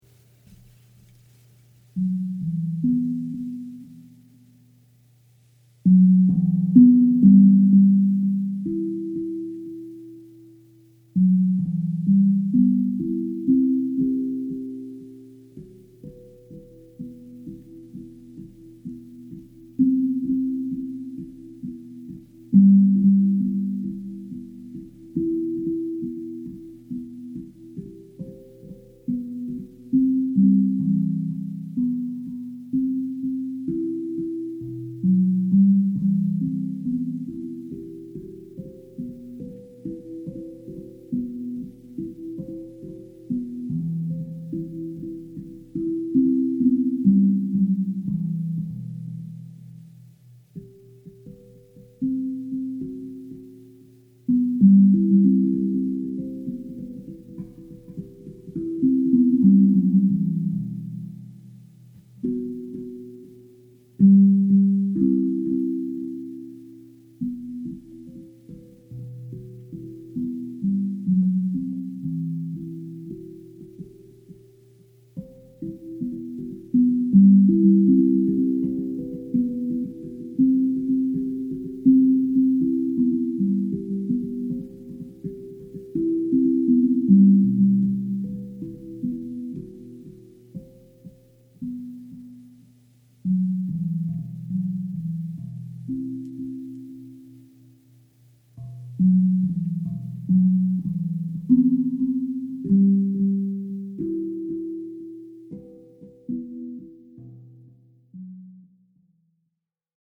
Click the play button below to experience a long moment with a zen tambour
Zen-Tambour-1.mp3